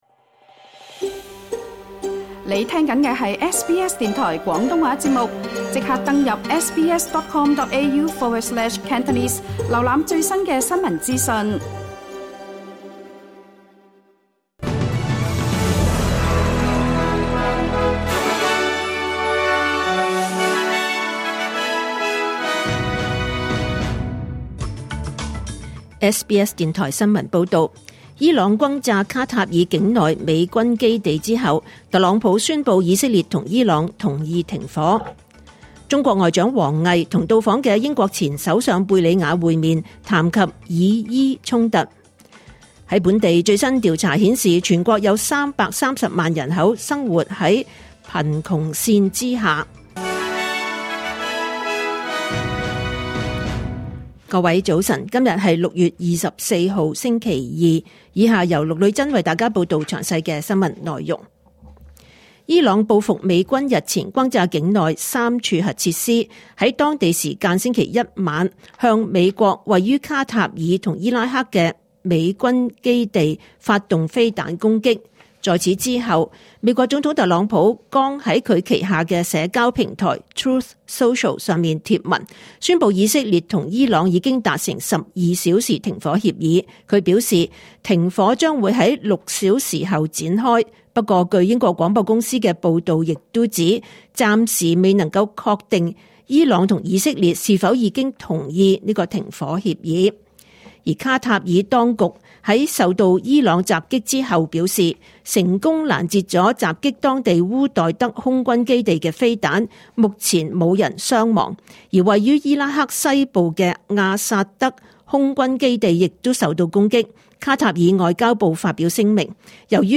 2025年6月24日 SBS 廣東話節目九點半新聞報道。